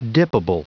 Prononciation du mot dippable en anglais (fichier audio)
Prononciation du mot : dippable